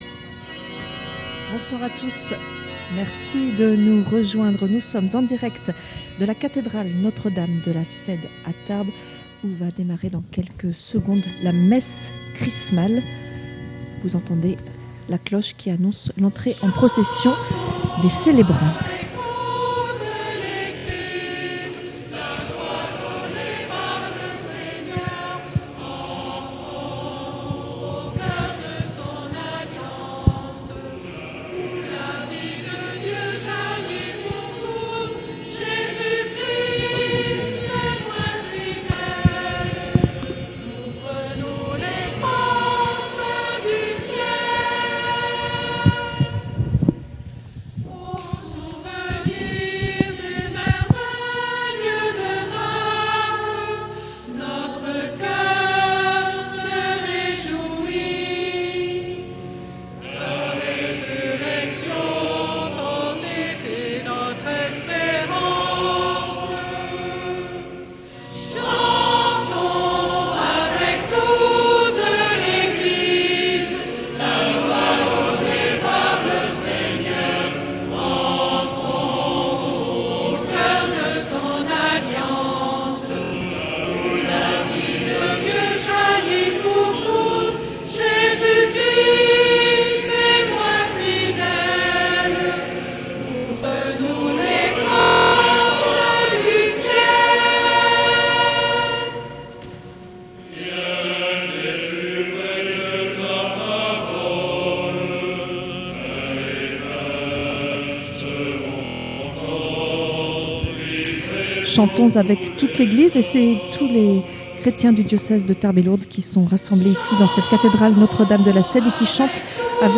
Messe Chrismale célébrée le mardi 15 avril 2025 et présidée par Mgr Micas depuis la Cathédrale Notre-Dame de la Sède à Tarbes.